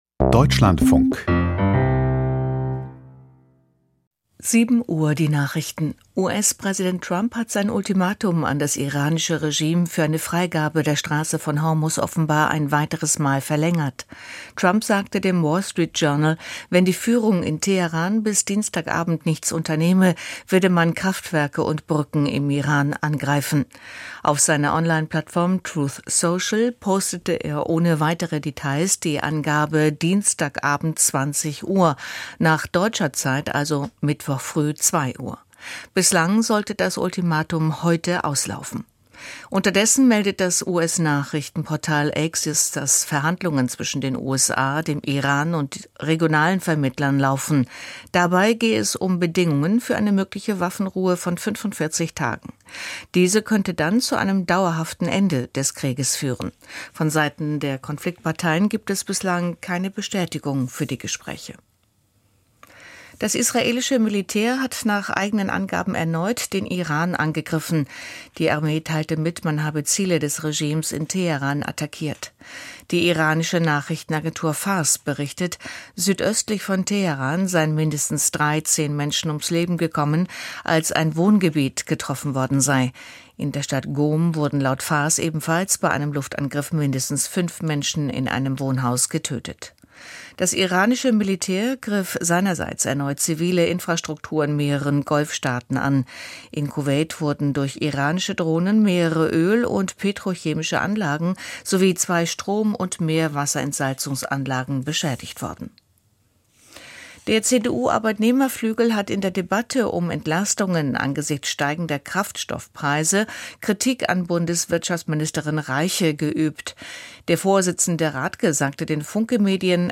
Die Nachrichten vom 06.04.2026, 07:00 Uhr